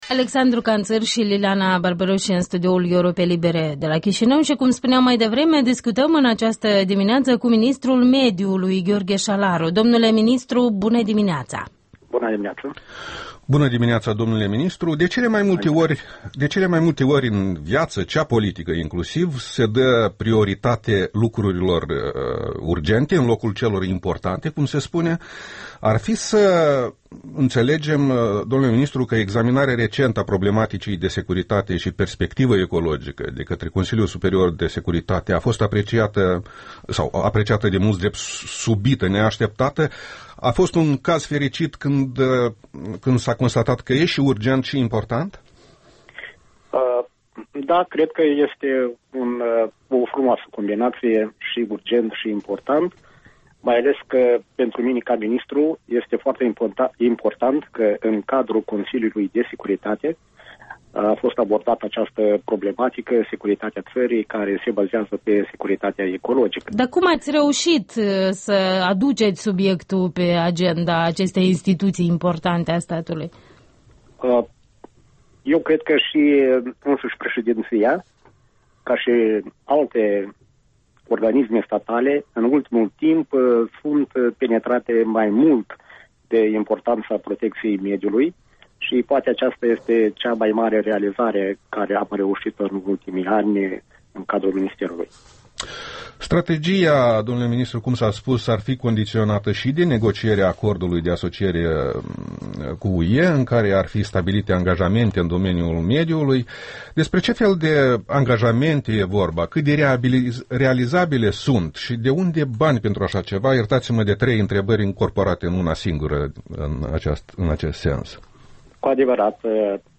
Interviul dimineții: despre ecologie cu ministrul de resort Gheorghe Șalaru